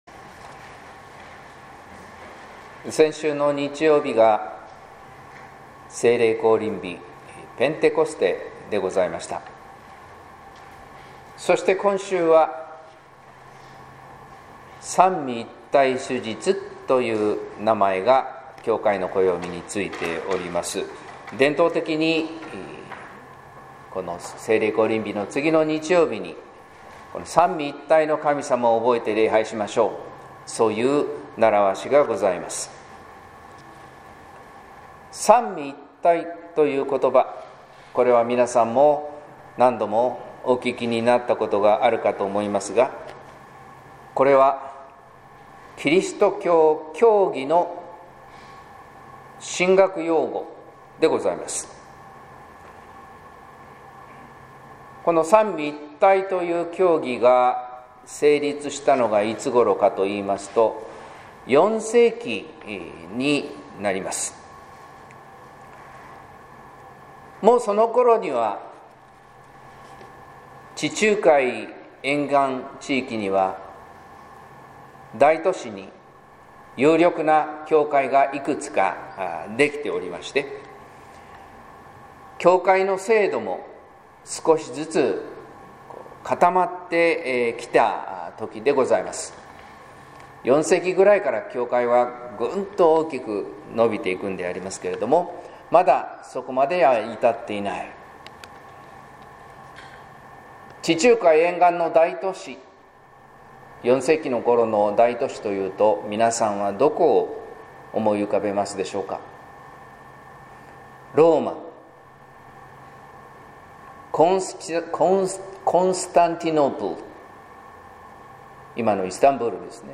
説教「聖霊が悟らせる」（音声版）